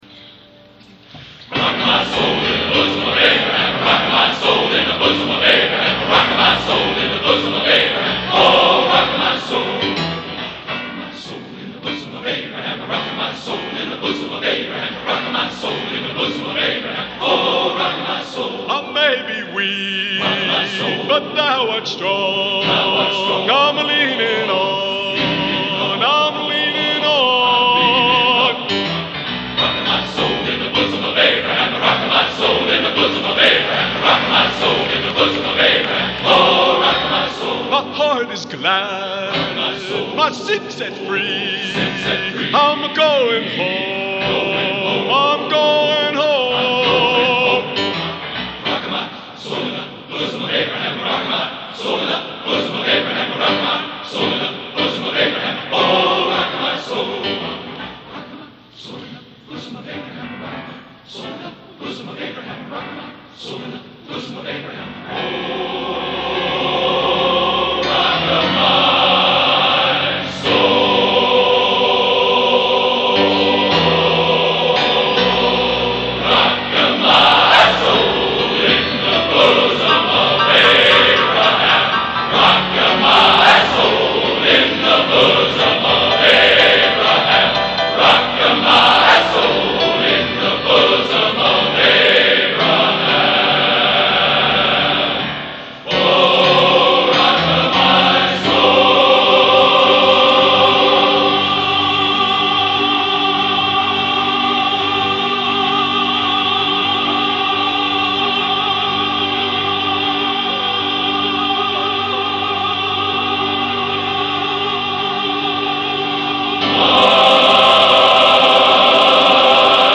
Genre: Spiritual | Type: